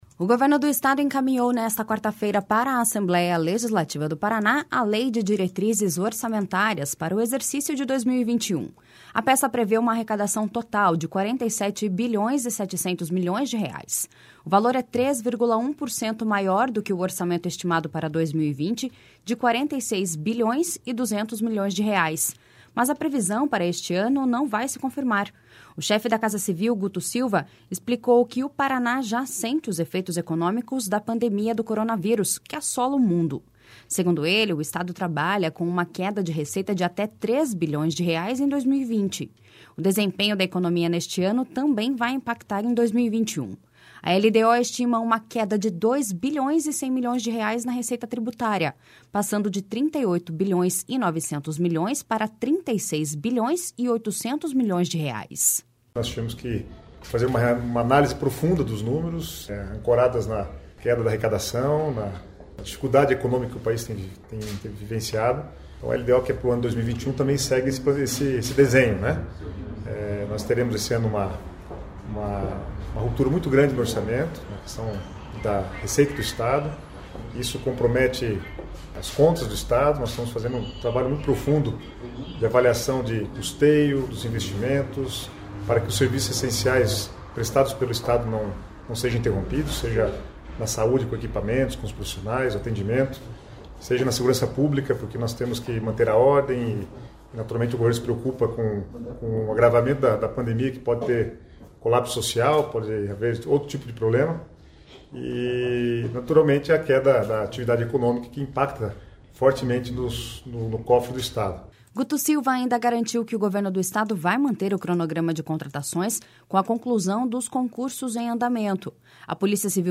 A LDO estima uma queda de 2 bilhões e 100 milhões na receita tributária, passando de 38 bilhões e 900 milhões para 36 bilhões e 800 milhões de reais.// SONORA GUTO SILVA.//
Segundo ele, a peça será analisada pelos deputados, podendo sofrer alterações.// SONORA ADEMAR TRAIANO.//